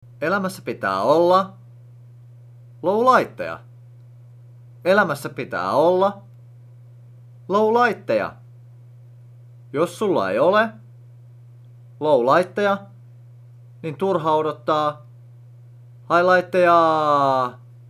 Hän on a cappella -lauluyhtye, jonka jäsenet ovat suurelta osin lahtelaistuneita.